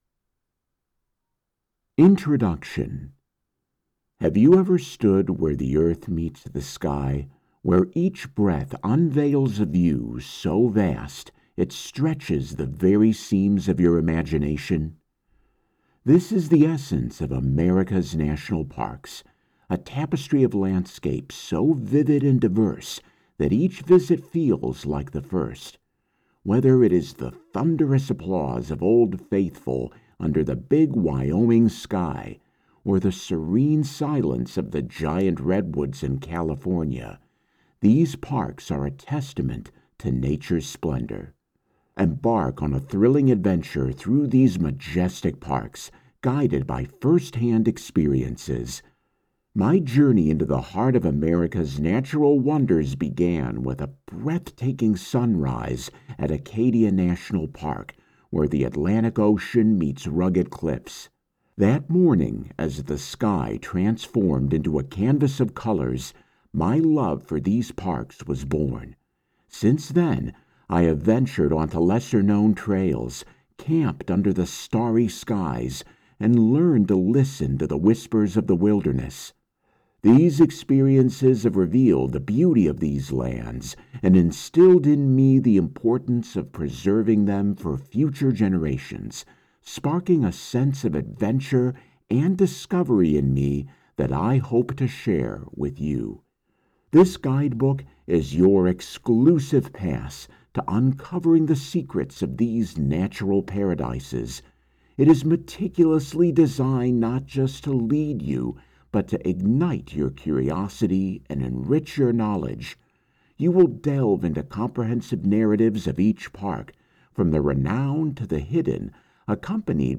Middle Aged
Audiobook